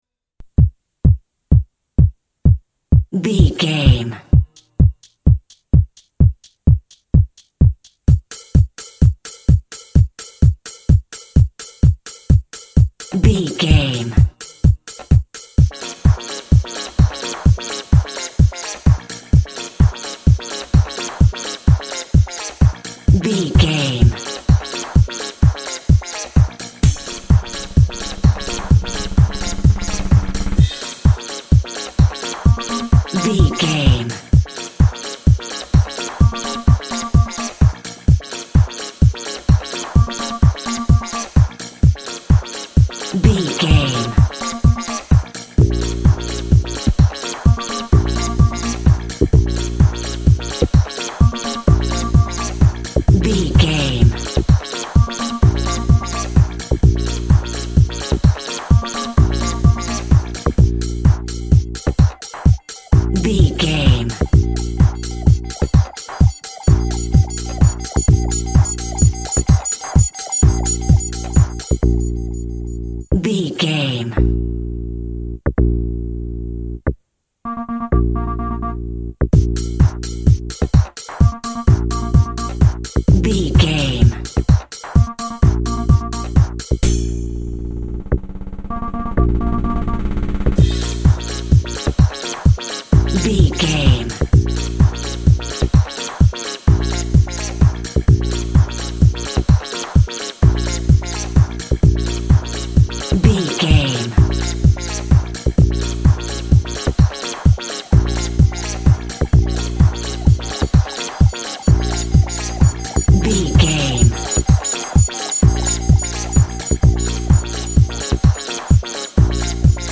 House Music From Japan.
Aeolian/Minor
B♭
groovy
futuristic
energetic
driving
drum machine
electronic
synth lead
synth bass
electronic drums
Synth Pads